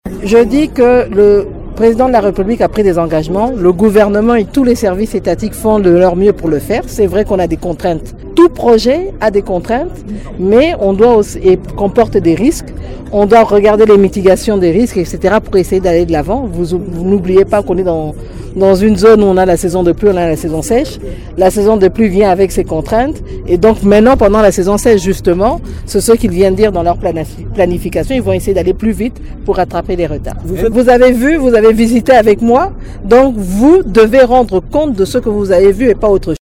Judith Suminwa a estimé que le retard accumulé pour la construction d'une route en terre battue serait rattrapé pendant la saison sèche: